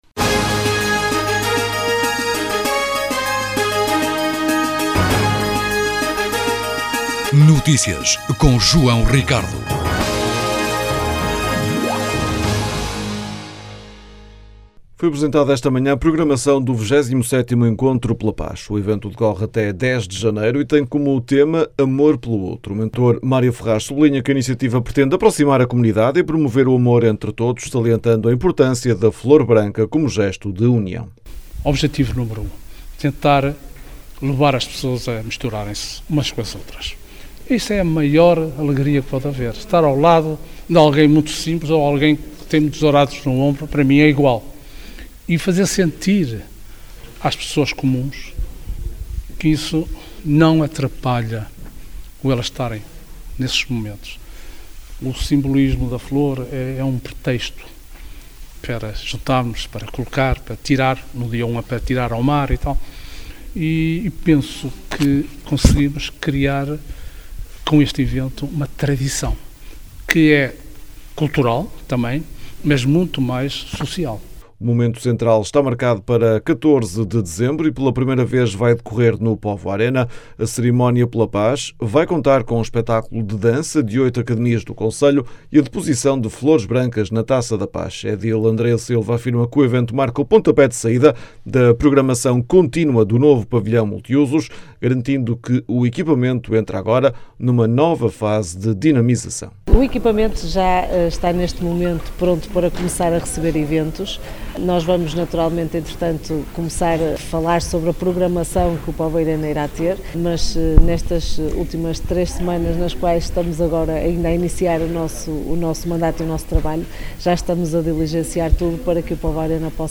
As declarações podem ser ouvidas na edição local.